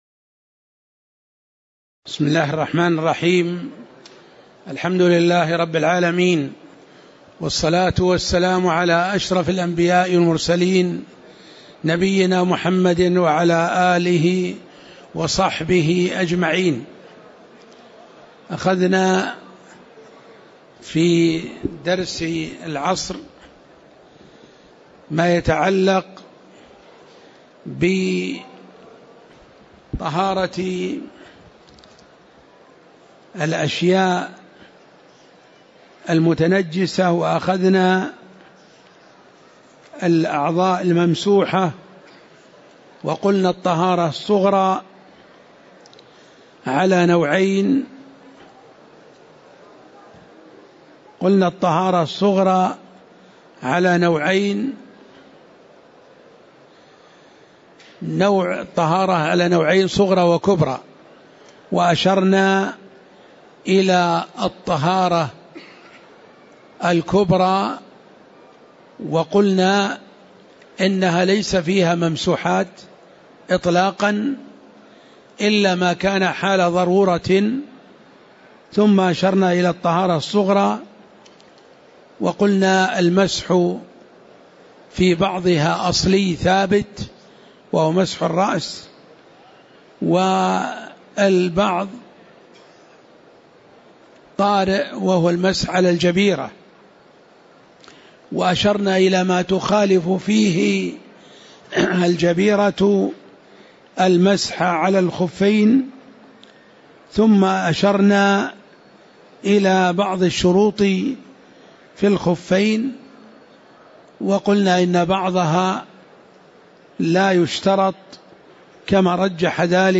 تاريخ النشر ١٥ شوال ١٤٣٨ هـ المكان: المسجد النبوي الشيخ